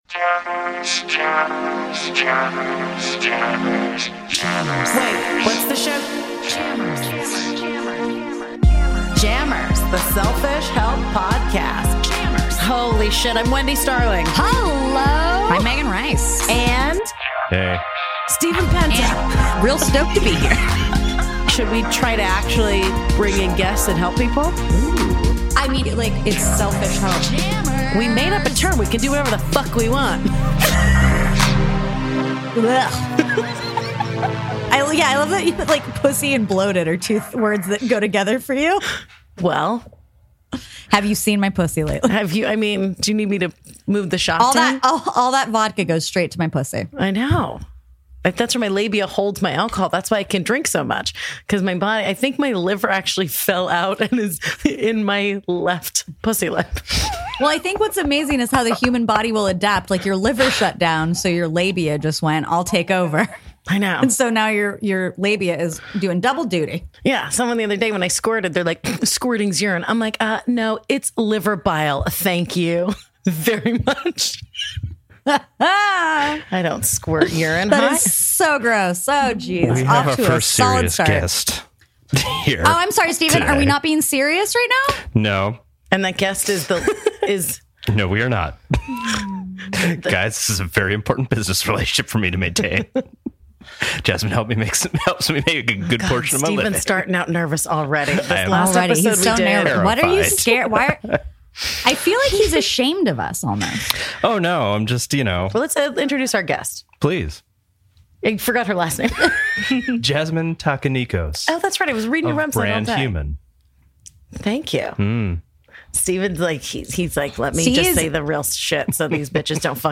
Two funny chicks? CHECK. Dope sound and graphics? CHECK.